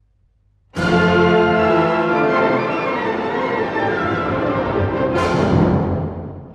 ↑古い録音のため聴きづらいかもしれません！（以下同様）
「祭り」ともいえるような、爆発的な喜びの感情で始まります。
民族的ともいえる主題が、熱狂的に繰り広げられます。